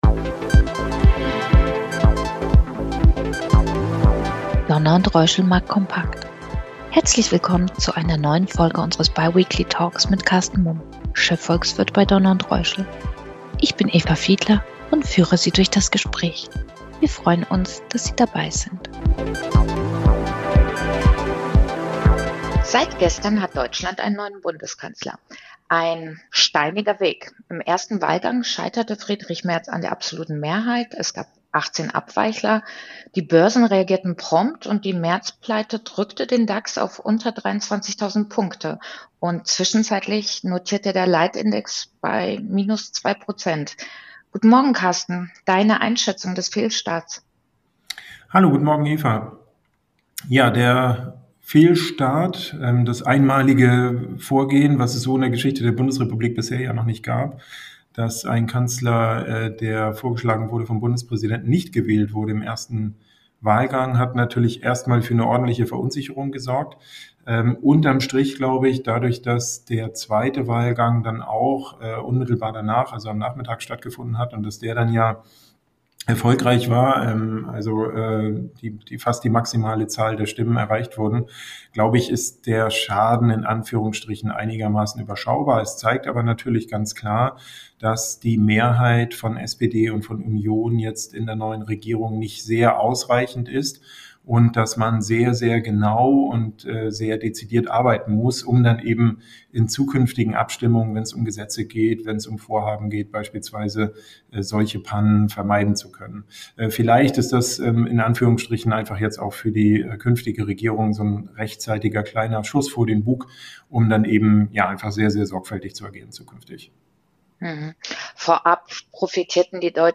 Der neue wöchentliche Talk zu aktuellen Marktthemen und einem Ausblick auf die globalen Kapitalmärkte.